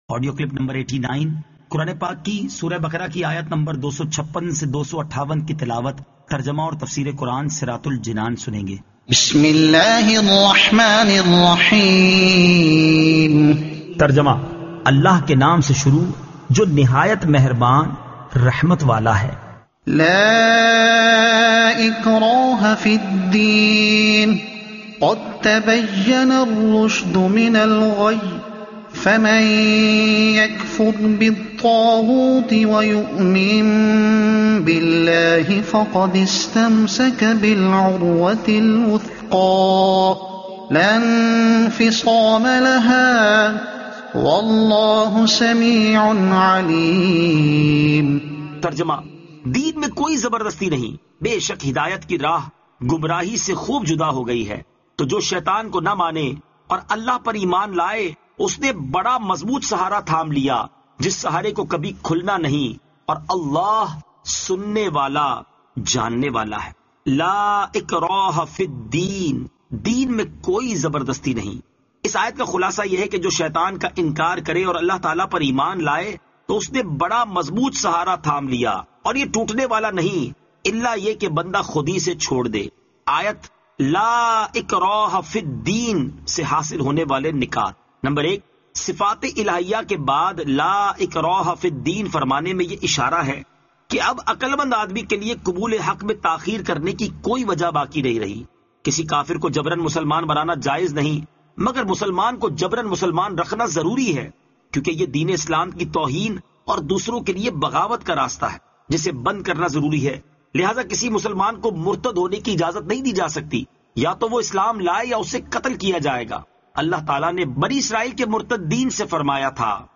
Surah Al-Baqara Ayat 256 To 258 Tilawat , Tarjuma , Tafseer